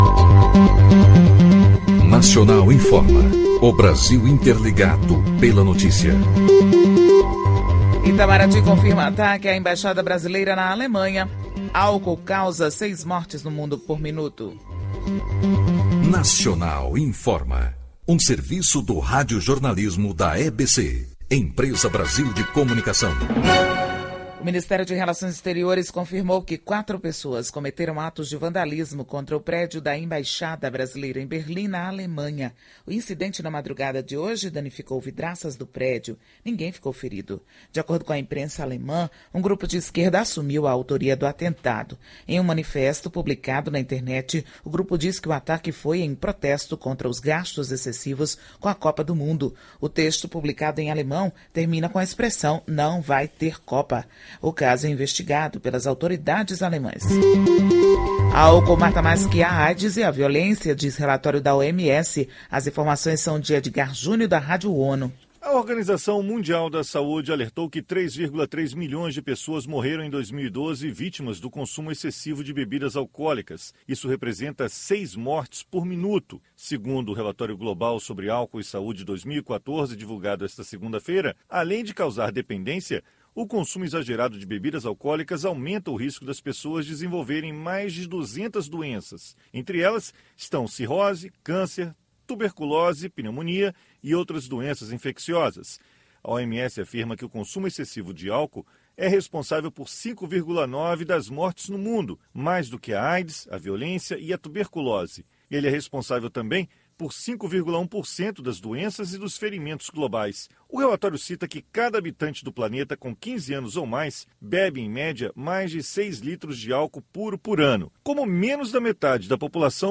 Nacional Informa : Boletim de notícias veiculado de hora em hora, com duração de até 4 minutos.